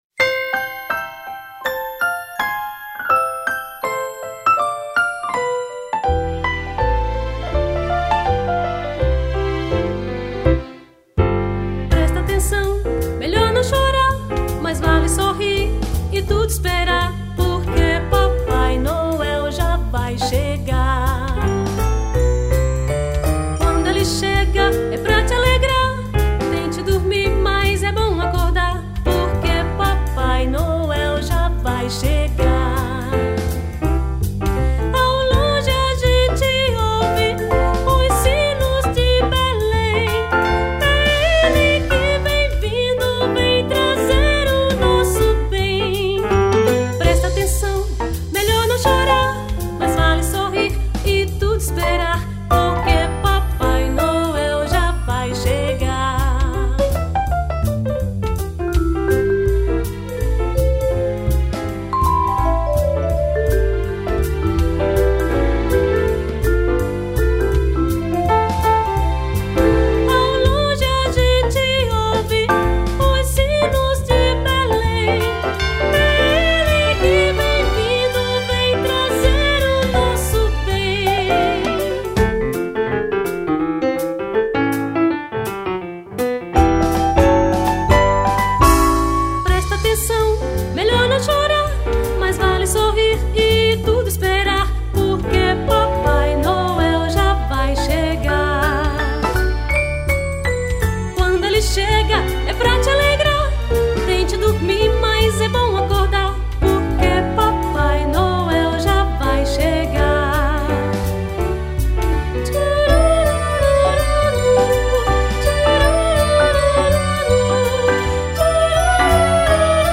1048   02:43:00   Faixa:     Canção Religiosa